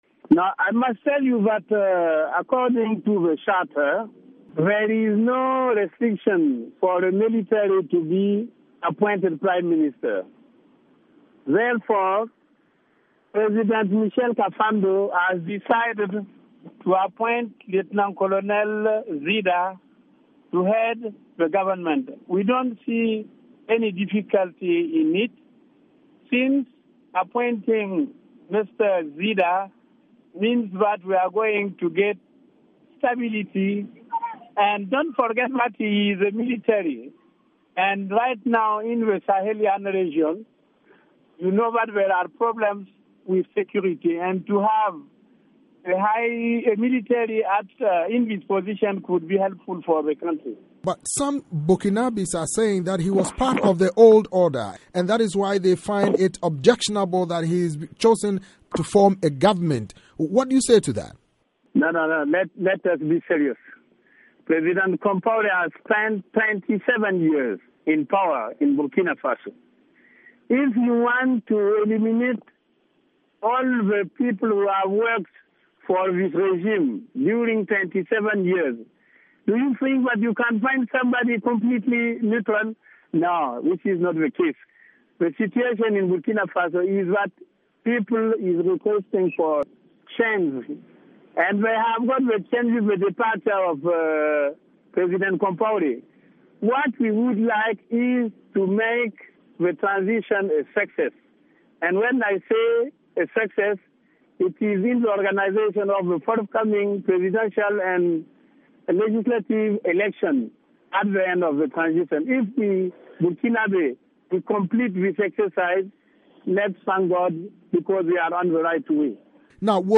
interview with Burkina Faso Opposition leader Ablasse Ouedraogo